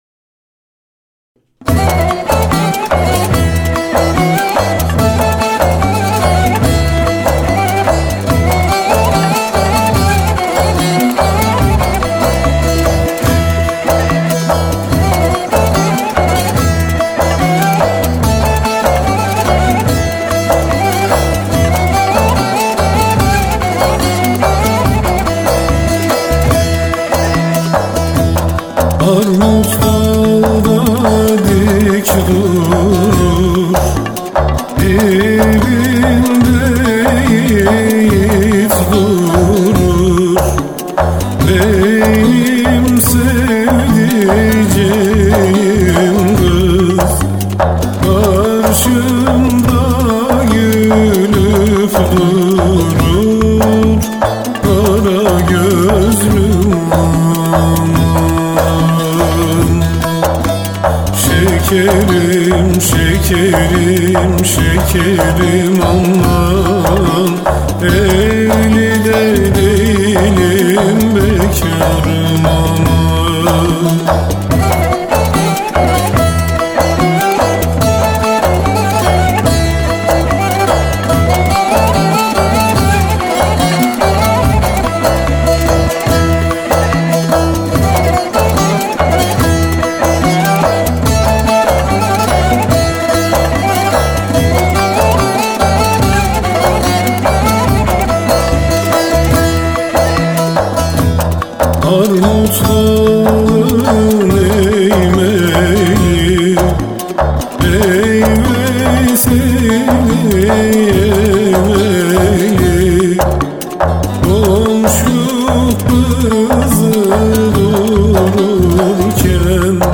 Türkü Künyesi
Yöresi - İliSakarya
MakamUşşak
Karar SesiLa
Usül4/4
Ses Genişliği6 Ses